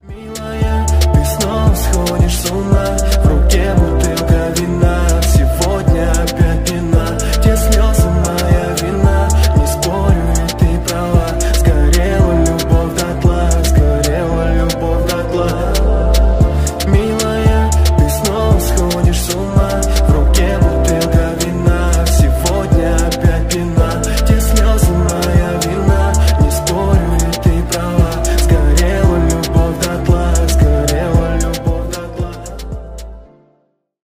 Поп Музыка
спокойные
грустные